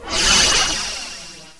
shutter.wav